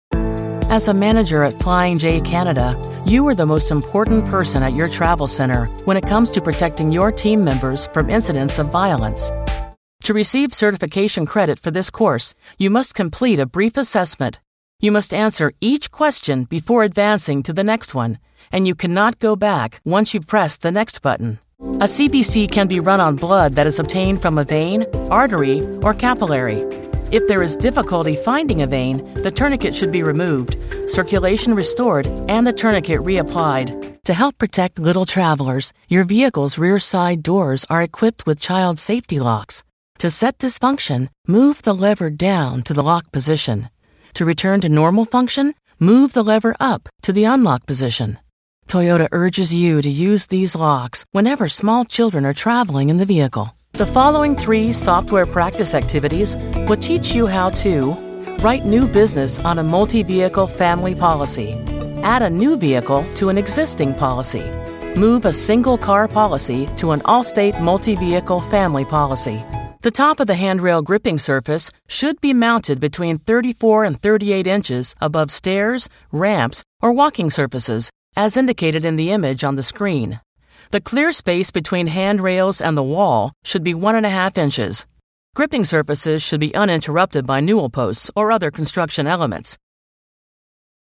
Female Voice Over, Dan Wachs Talent Agency.
Mature, Attention-Grabber, Conversational
e-Learning